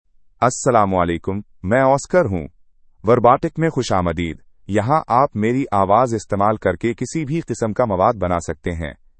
OscarMale Urdu AI voice
Oscar is a male AI voice for Urdu (India).
Voice sample
Listen to Oscar's male Urdu voice.
Male
Oscar delivers clear pronunciation with authentic India Urdu intonation, making your content sound professionally produced.